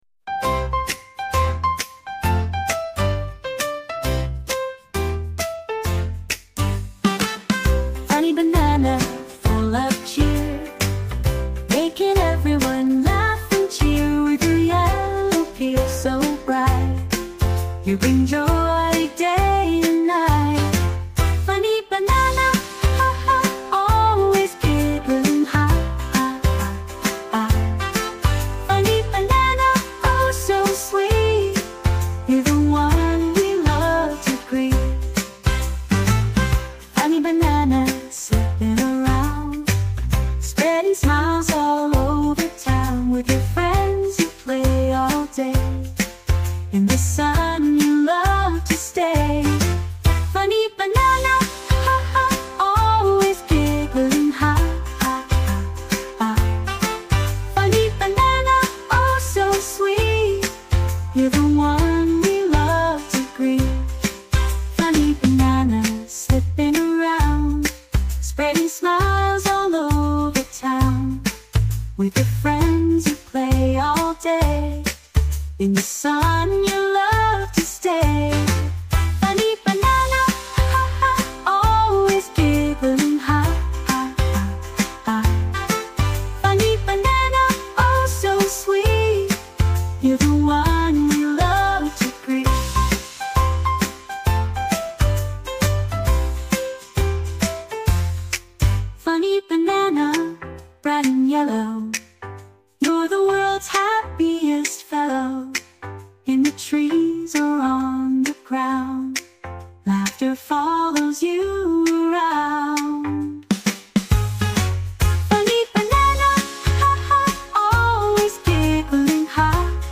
Nursery Rhymes & Kids Songs